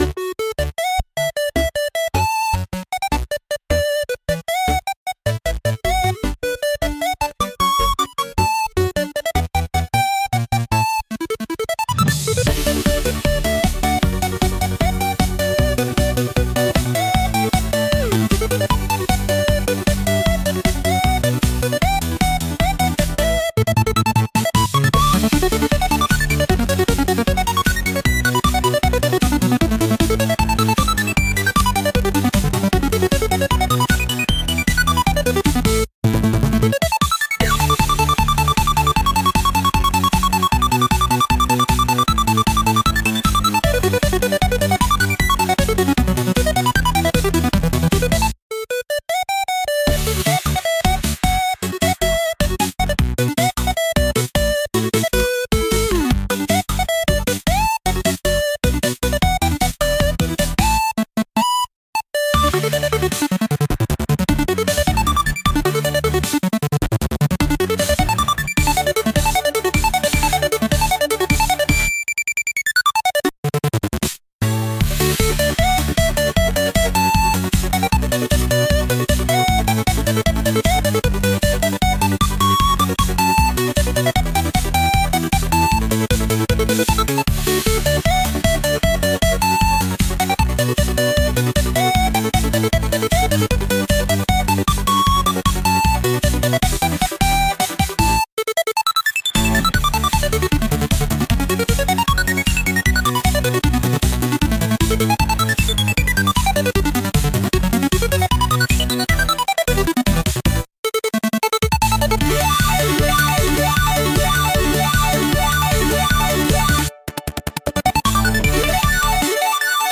乗り物に乗って出かけるようなピコピコサウンド曲です。